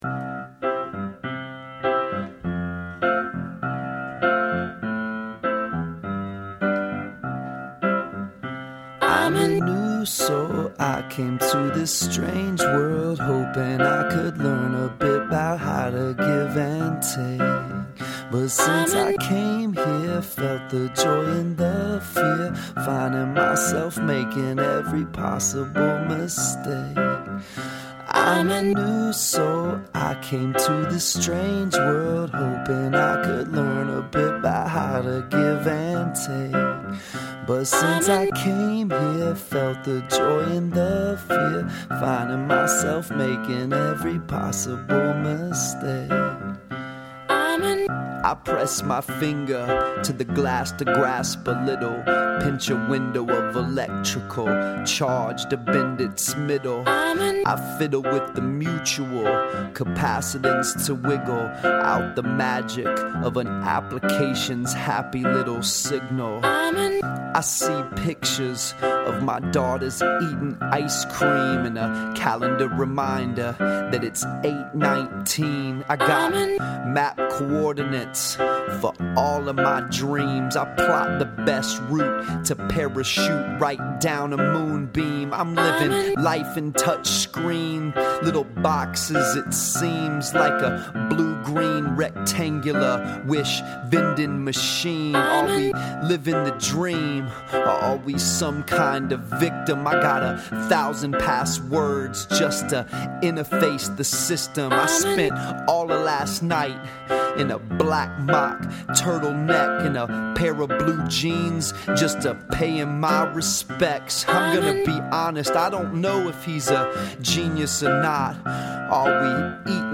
Awesome…Like the change up of the slow beat and singing.